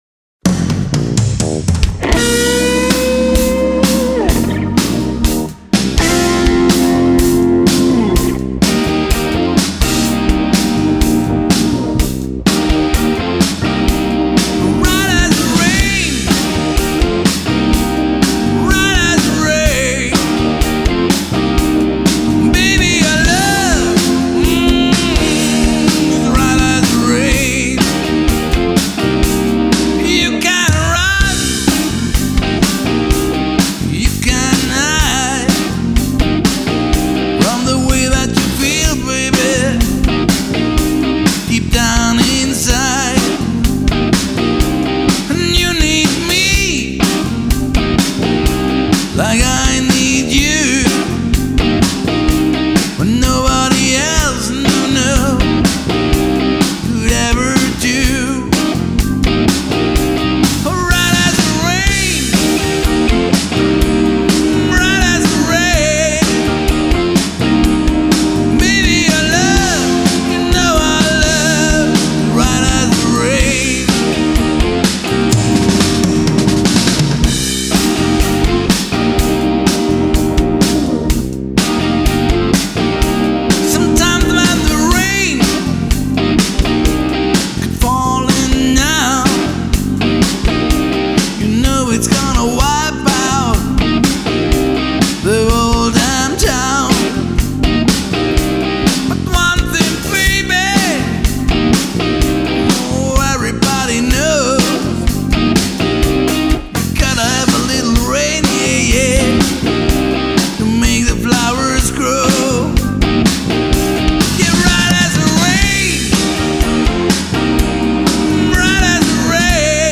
Vintage Hammond B3